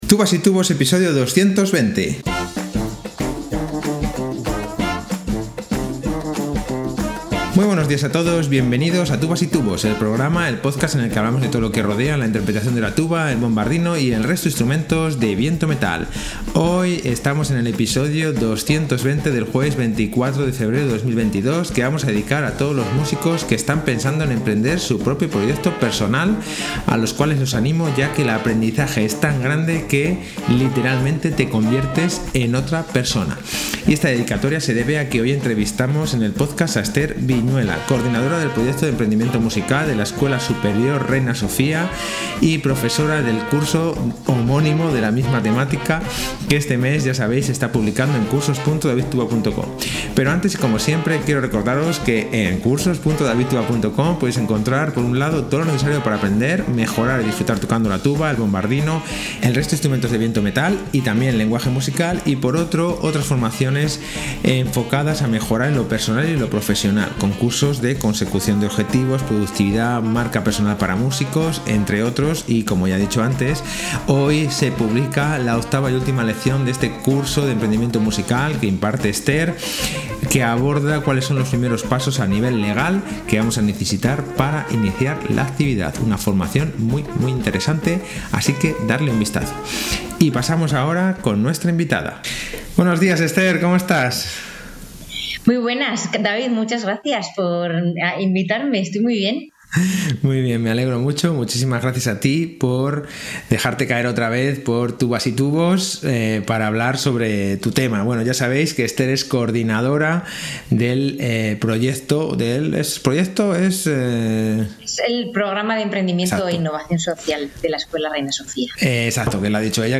Interesantísima entrevista sobre Empredimiento Musical